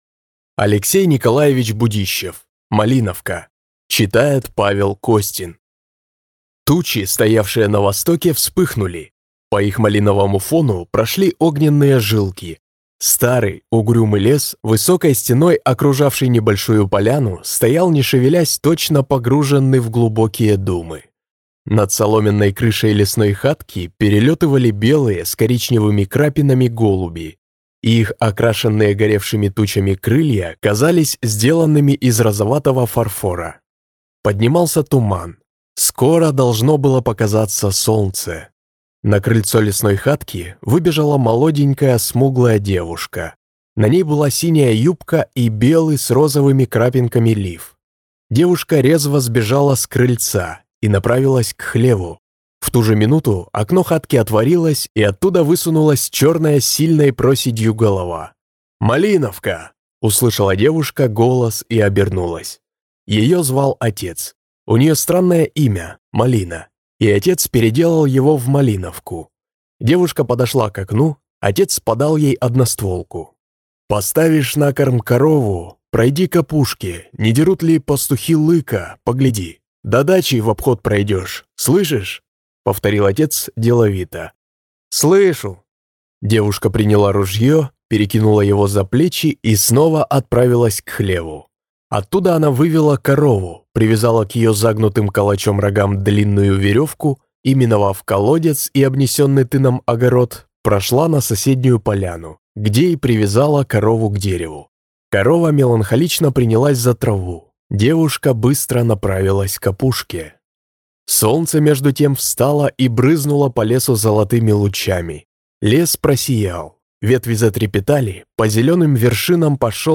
Аудиокнига Малиновка | Библиотека аудиокниг